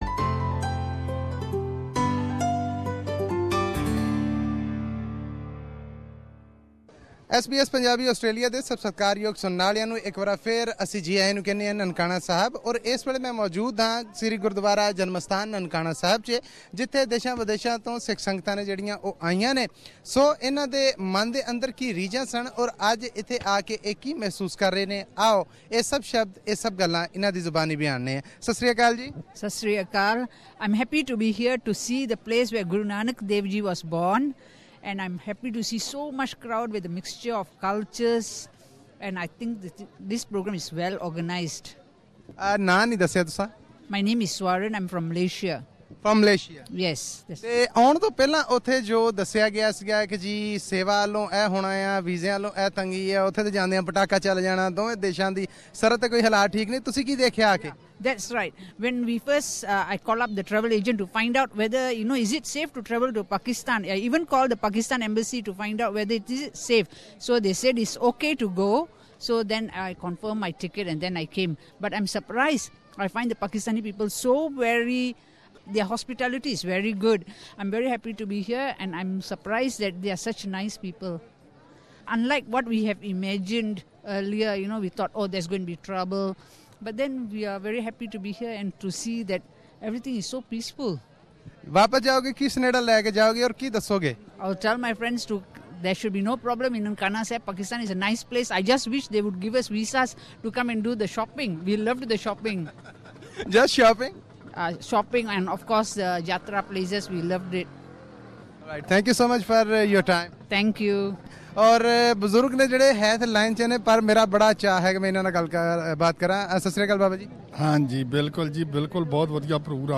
Pilgrims visiting Nankana Sahib share their experiences with SBS Punjabi
Source: SBS Punjabi Here is a report about Day 2 of the celebrations, when various pilgrims spoke to SBS Punjabi about the arrangements made by the government of Pakistan, and about the welcome they've received from the local residents.